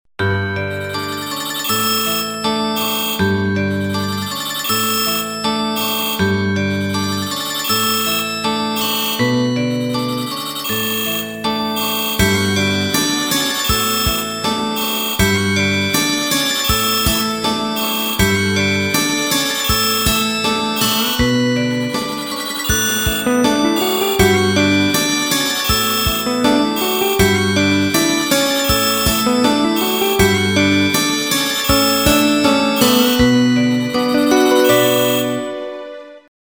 Freeze airy sound effect ringtone free download
Sound Effects